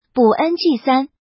ňg
ng3.mp3